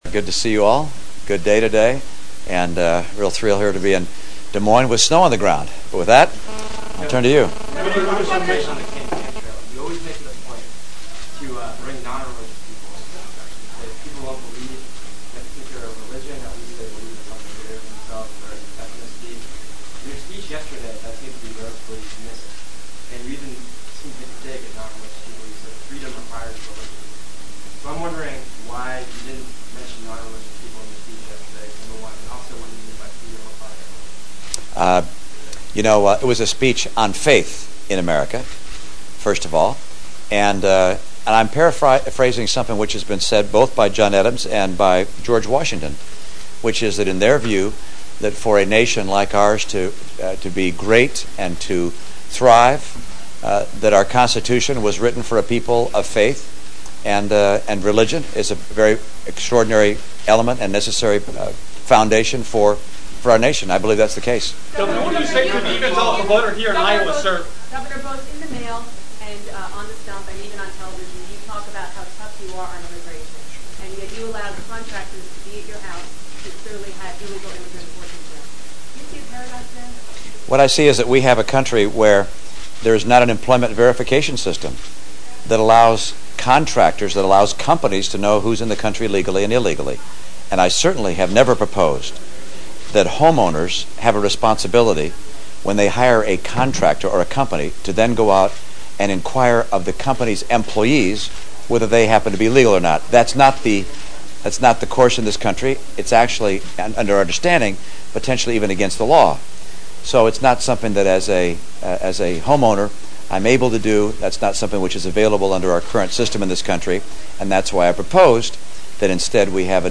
Romney was pressed on the issue during a news conference this afternoon in Des Moines and Romney scoffed at a reporter’s suggestion that as someone who has proposed a crackdown on illegal immigration he should have "gone the extra mile" to ensure workers on his property were legal immigrants.
AUDIO: Romney news conference (mp3 runs 7 min)